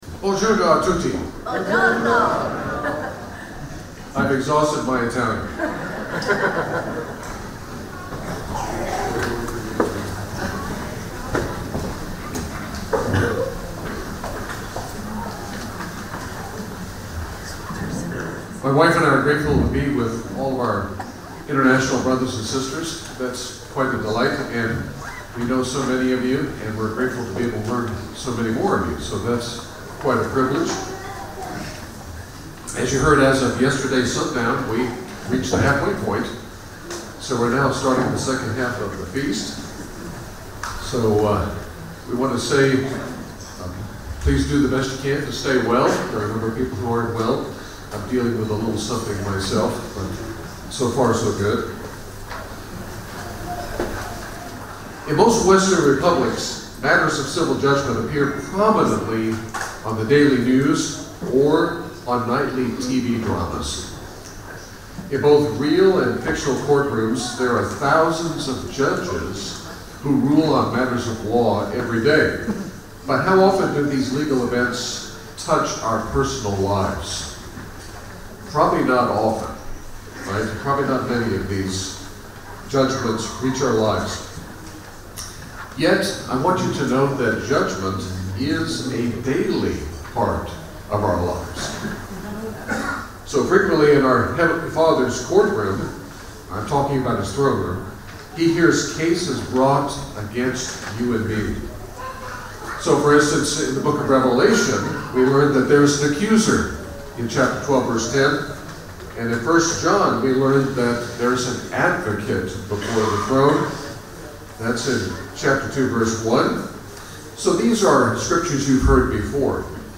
FoT 2024 Marina di Grosseto (Italy): 5th day